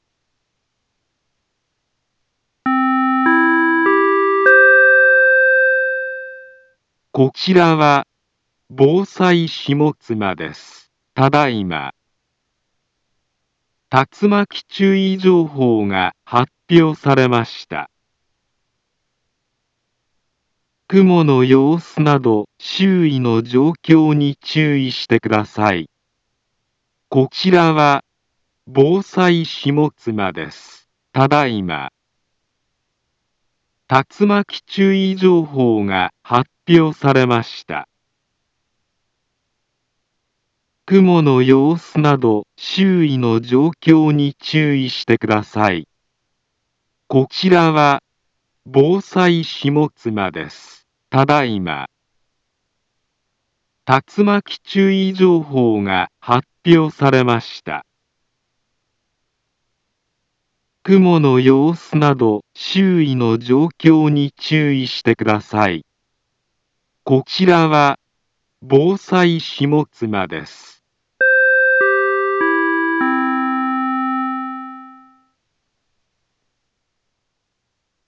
Back Home Ｊアラート情報 音声放送 再生 災害情報 カテゴリ：J-ALERT 登録日時：2025-08-06 17:04:47 インフォメーション：茨城県北部、南部は、竜巻などの激しい突風が発生しやすい気象状況になっています。